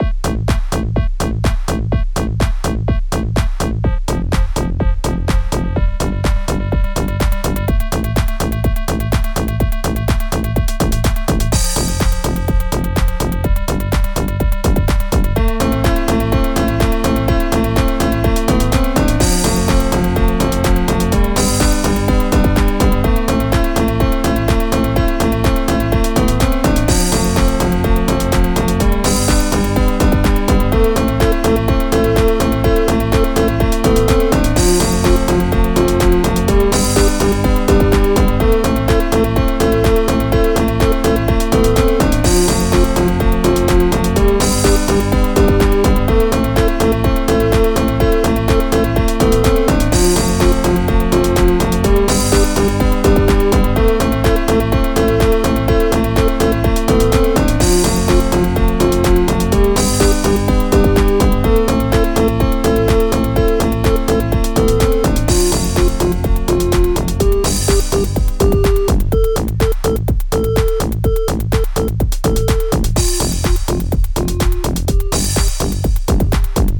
Extended Module
FastTracker 2 v1.04